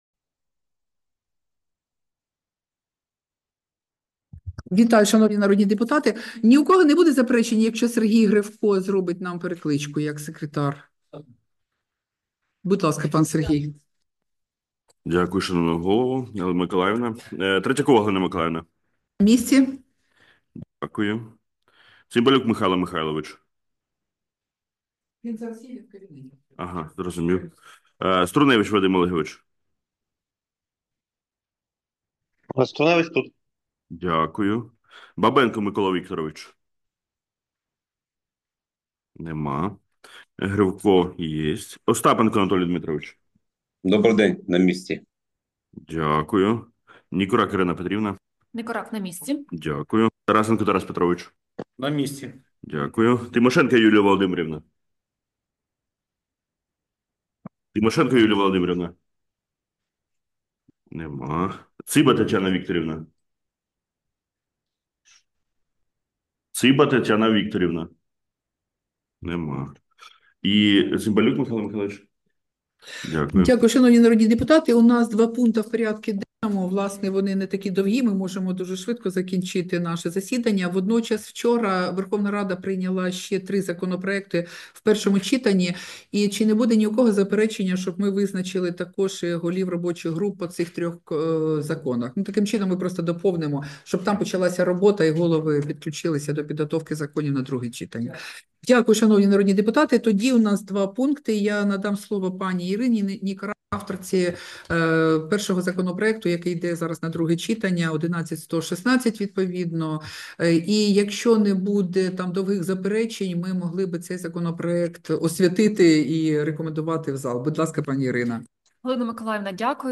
Засідання Комітету від 6 грудня 2024 року